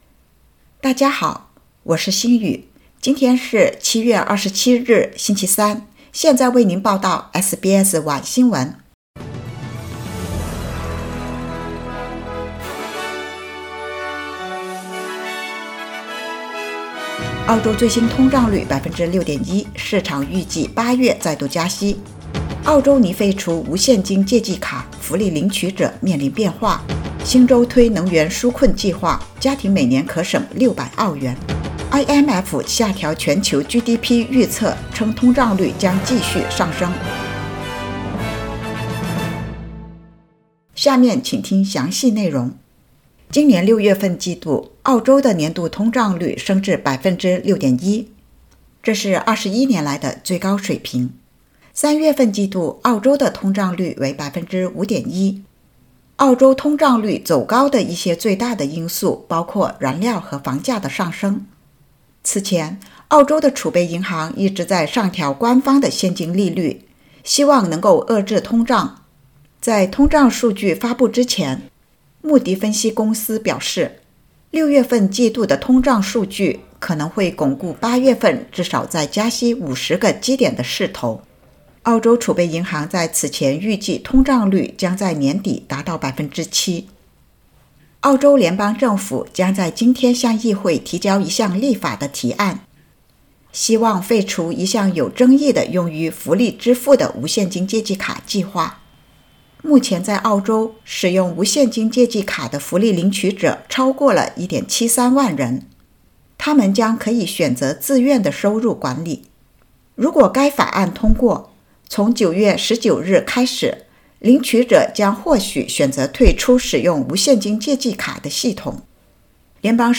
SBS Mandarin evening news